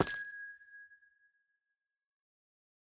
Minecraft Version Minecraft Version 25w18a Latest Release | Latest Snapshot 25w18a / assets / minecraft / sounds / block / amethyst / step2.ogg Compare With Compare With Latest Release | Latest Snapshot